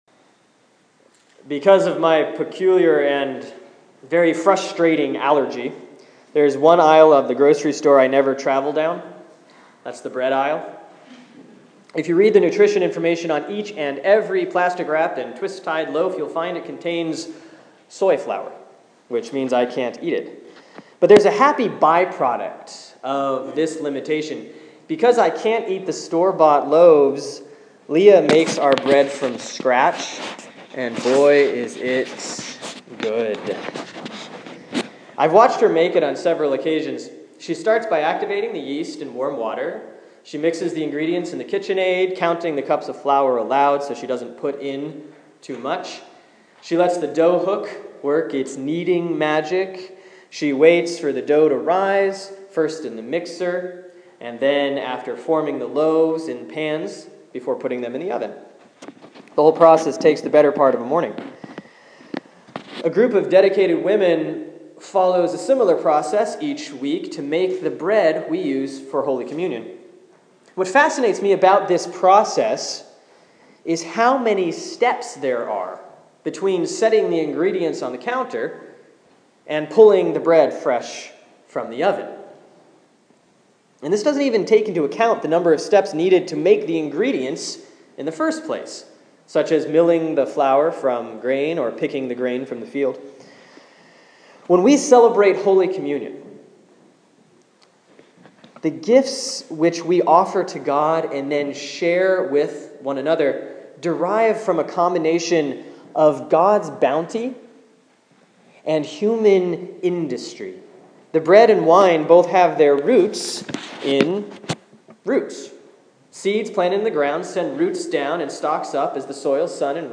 Sermon for Sunday, October 5, 2014 || Proper 22A || *